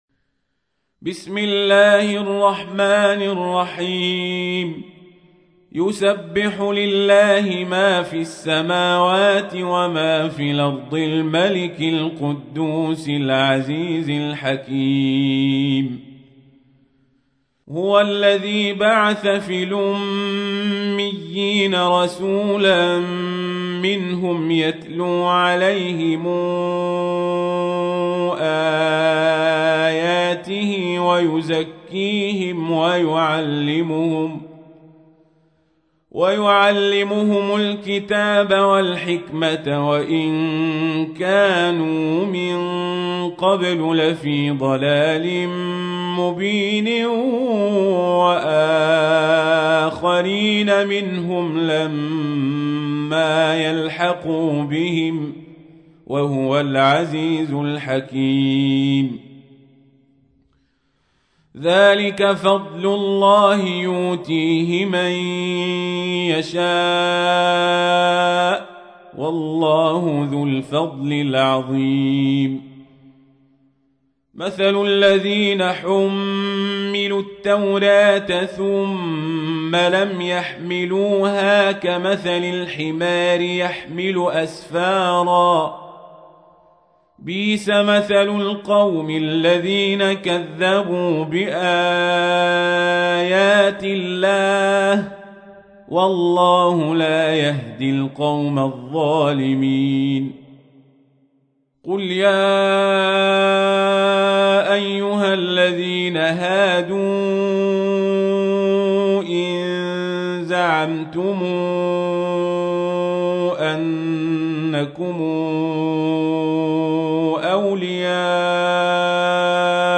تحميل : 62. سورة الجمعة / القارئ القزابري / القرآن الكريم / موقع يا حسين